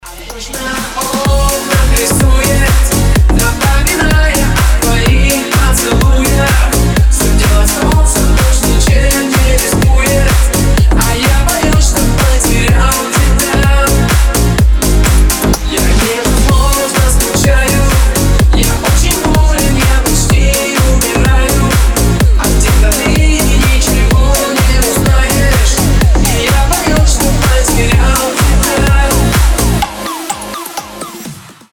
громкие
remix
deep house
Club House
Хороший ремикс популярной песни из 2000-х.